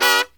FALL HIT03-R.wav